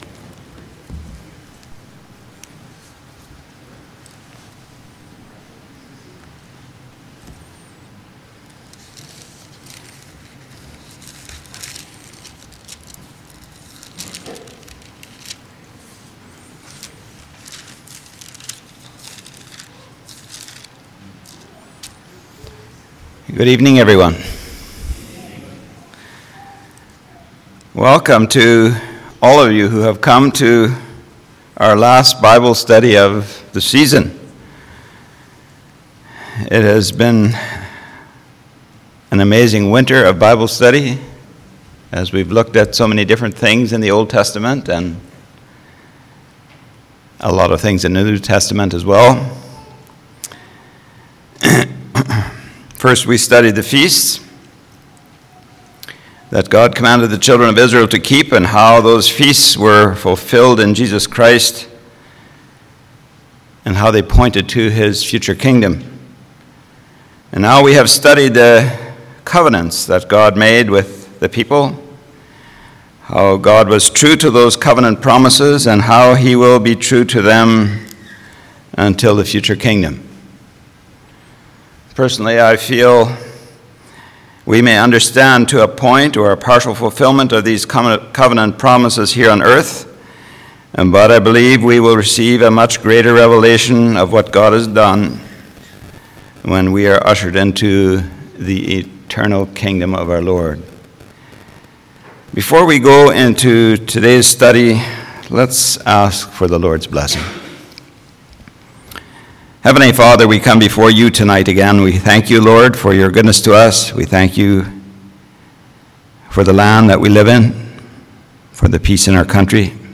Church Bible Study – Covenants – New Covenant/Grace
Service Type: Church Bible Study